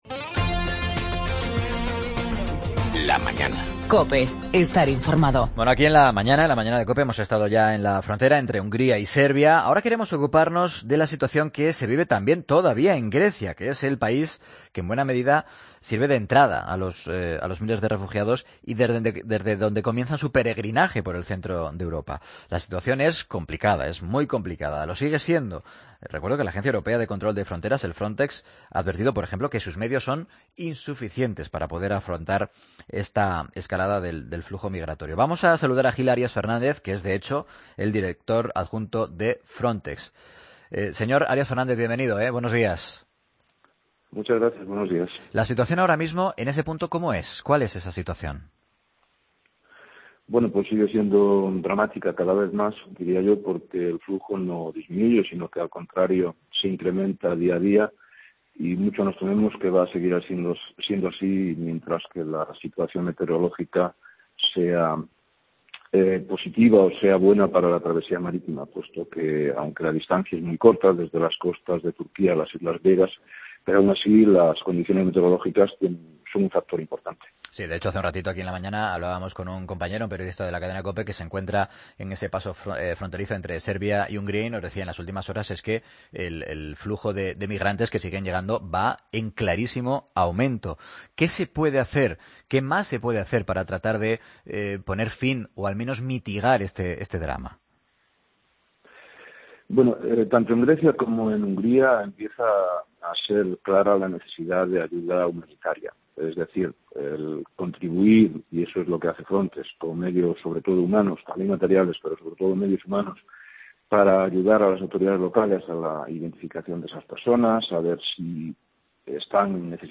AUDIO: El Director Adjunto de FRONTEX, Gil Arias Fernández ha analizado en La Mañana la actual crisis migratoria que vive Europa